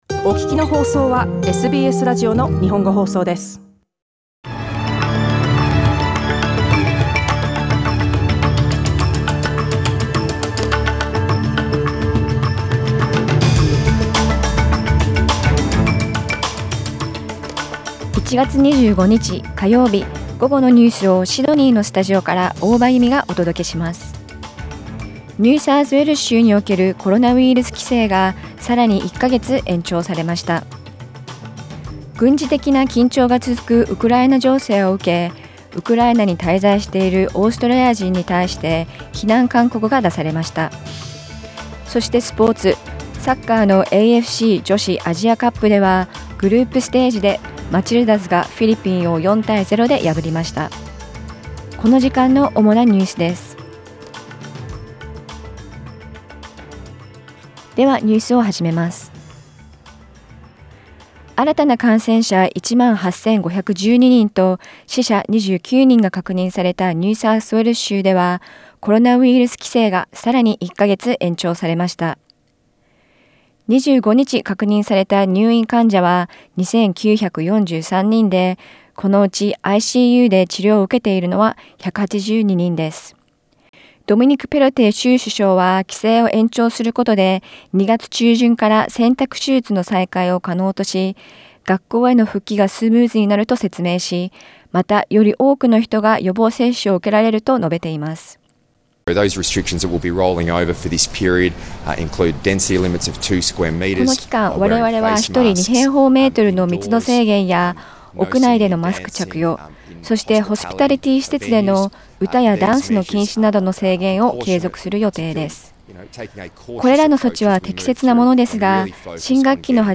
1月25日午後のニュース
Afternoon news in Japanese, 25 January 2022